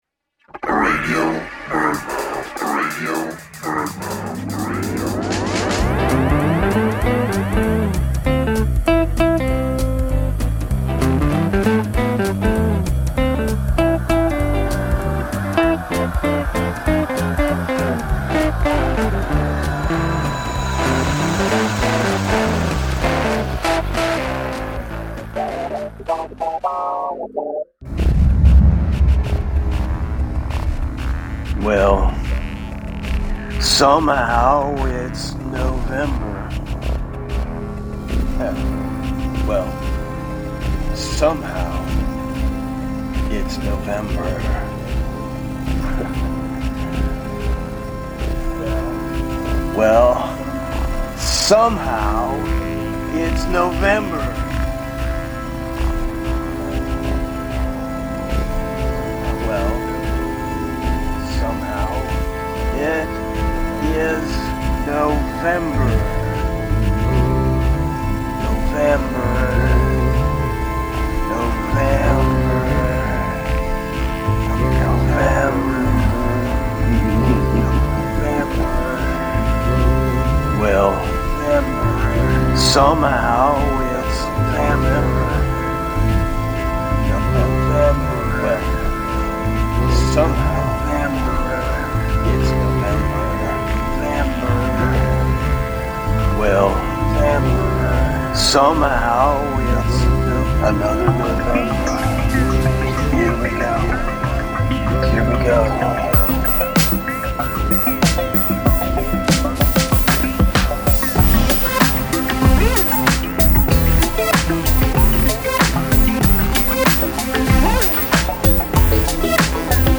"Radio BirdMouth" is a loose-form audio road trip woven together from fragmentary spoken word narratives and sound manipulations.